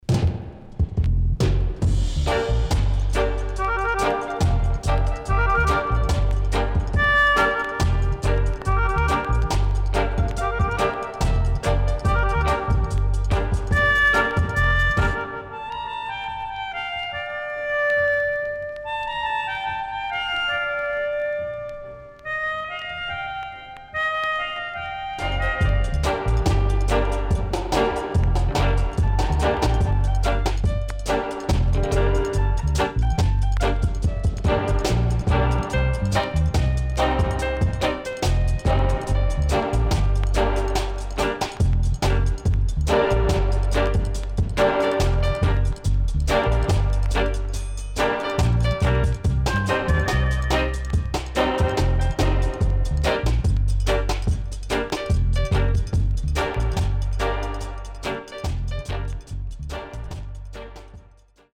REGGAE / ROOTS
Killer Melodica Inst & Dubwise
SIDE A:全体的にチリノイズがあり、所々プチパチノイズ入ります。